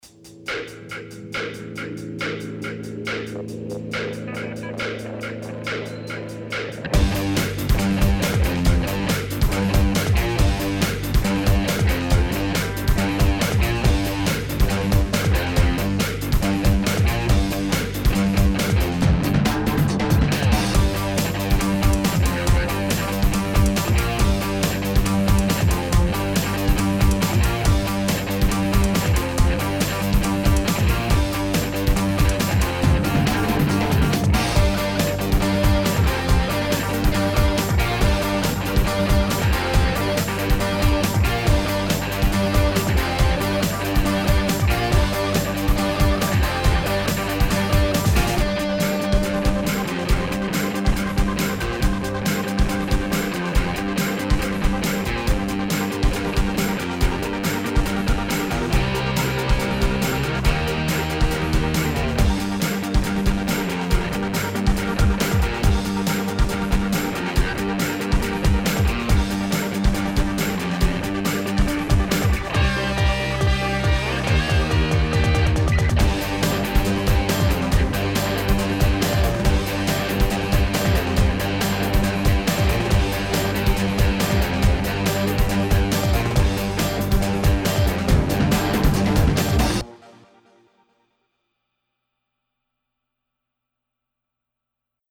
Μουσική από την παράσταση
. Σιάγας, Αδάμ - Σύνθεση